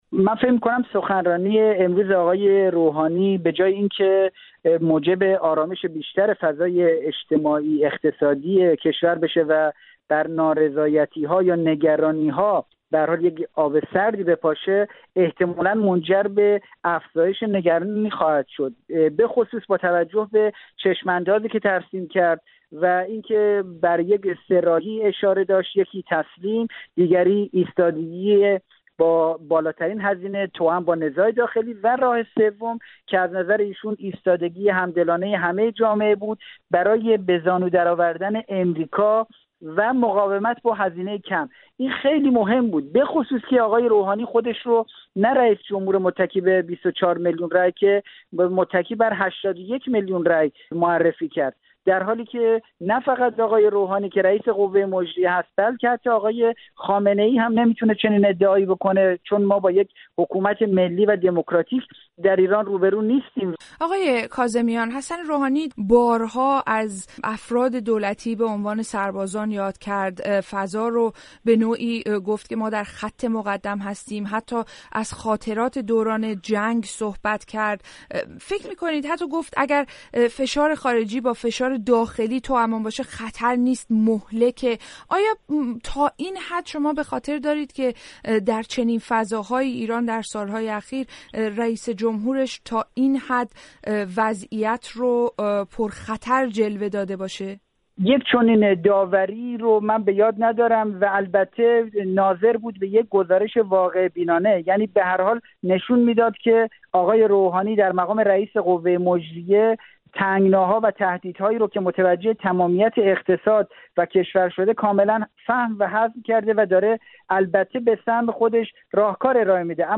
ارزیابی سخنرانی حسن روحانی درباره ادامه کار دولتش در گفت‌وگو